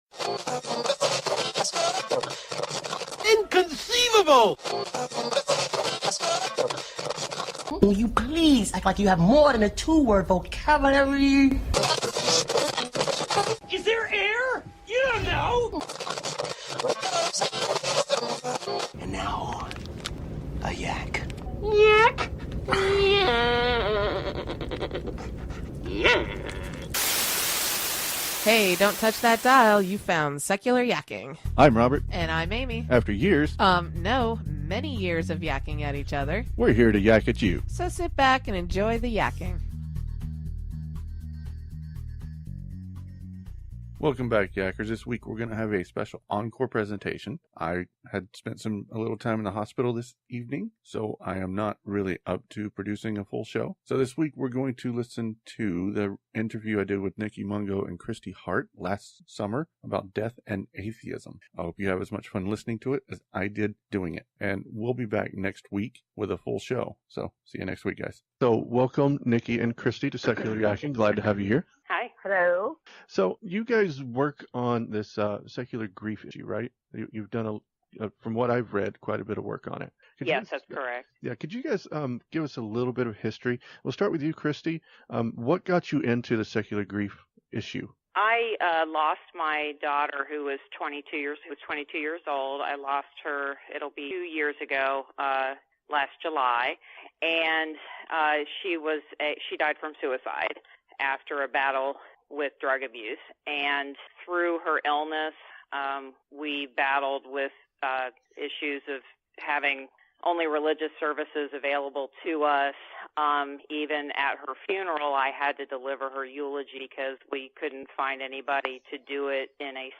This week we revisit an interview I did last summer